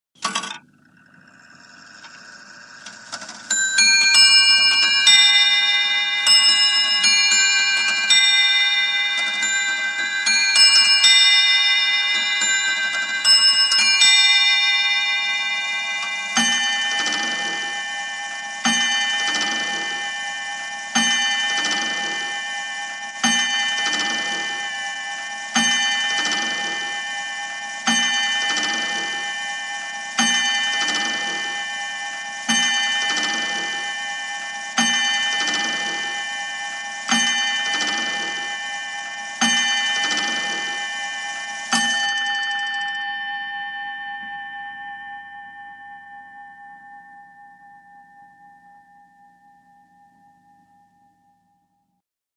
CLOCKS ANTIQUE CLOCK: INT: Antique clock bell strikes 12 0`clock.